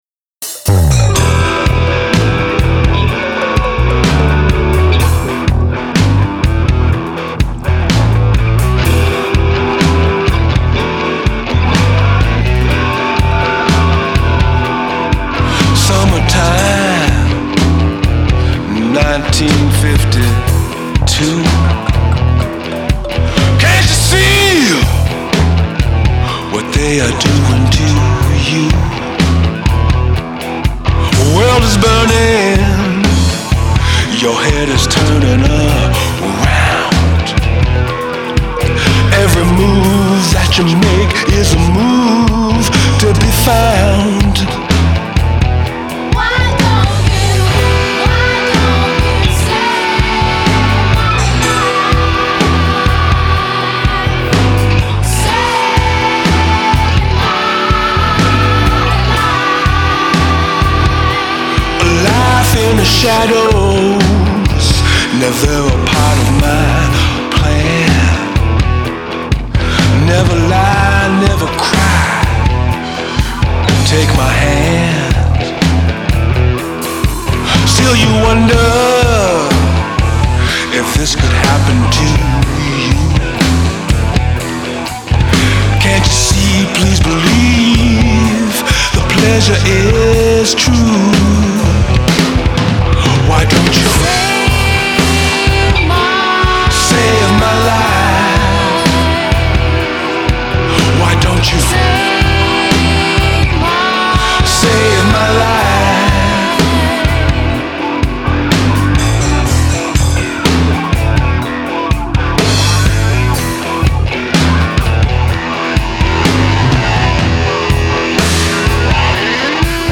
Жанр: Blues-Rock